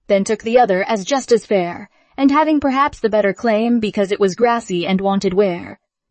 tortoise-tts-v2 like 226